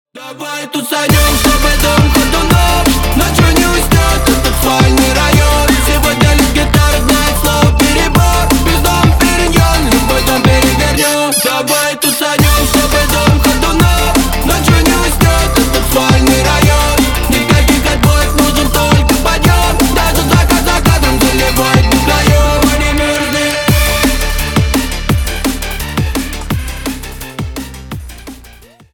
Поп Музыка
громкие # клубные